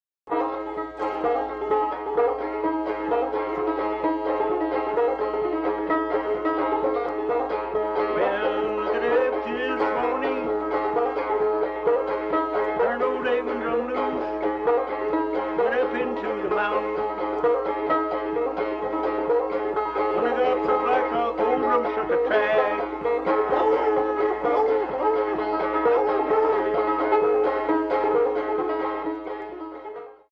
Old Time Virginia Banjo Picker
banjo